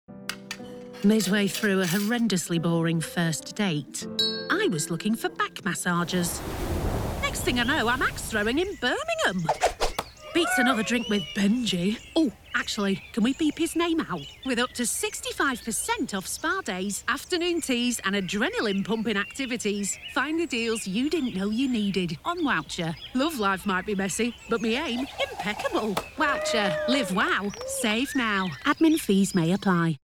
Female
English (British)
Yng Adult (18-29), Adult (30-50)
Radio Commercials
Wowcher Ad - Northern Accent
All our voice actors have professional broadcast quality recording studios.